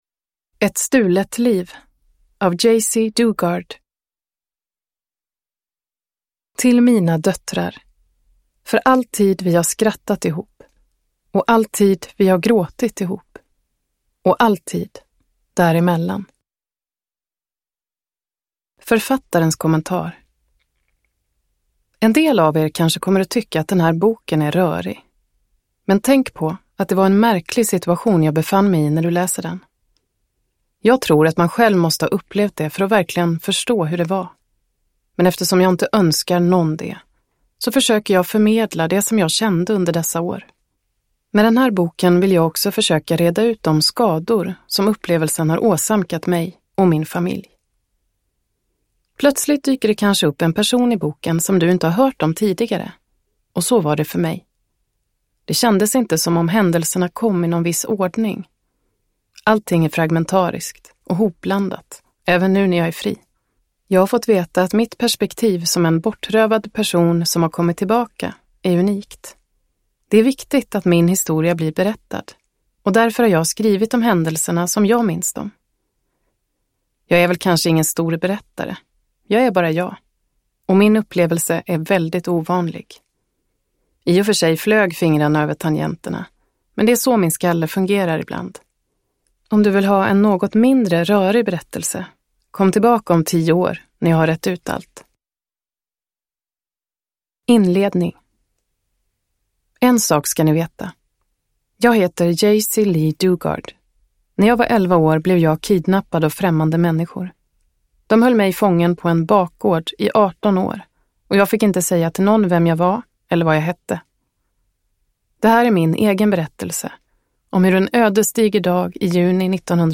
Ett stulet liv – Ljudbok – Laddas ner